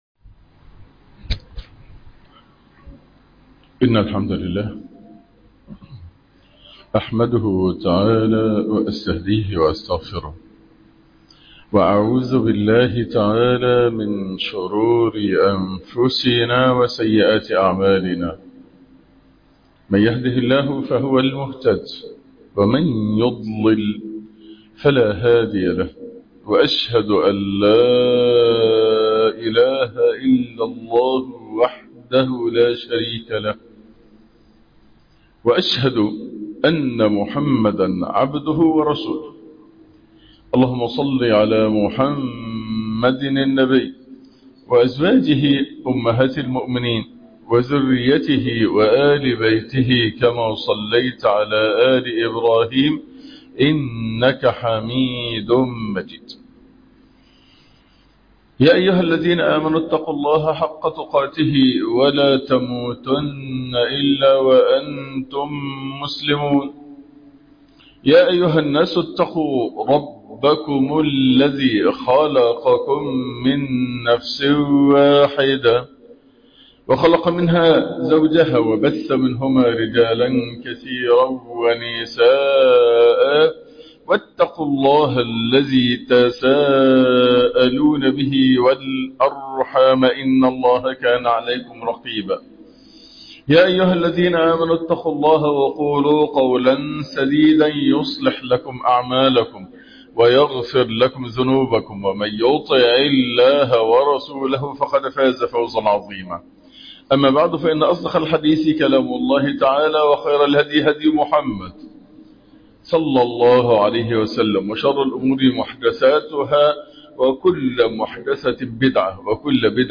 خـلـق الـحـيـاء - خـطـبـة الجمعة